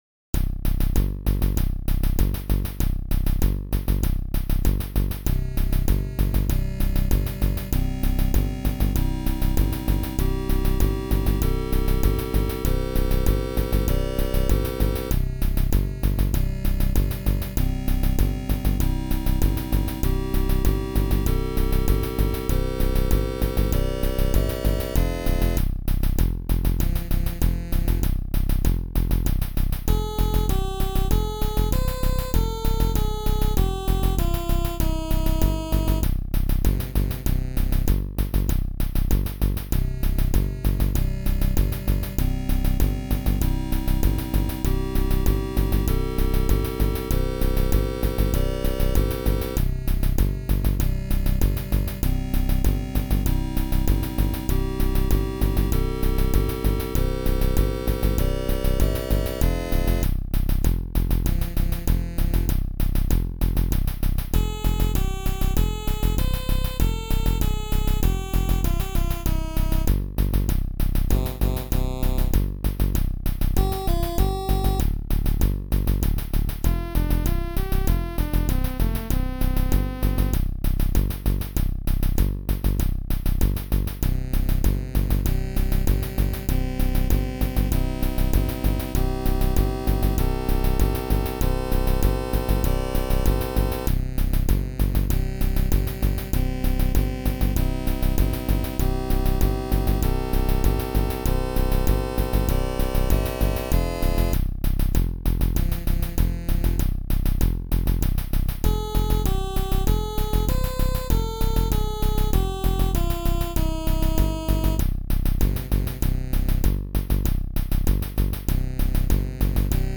Synth-Wave